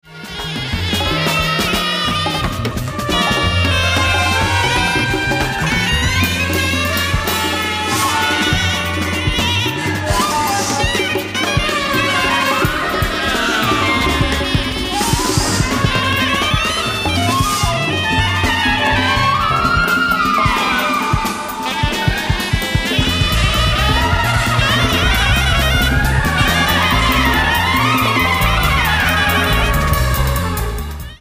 drums
trumpet/flugelhorn
elec.guitar/fork guitar/sitar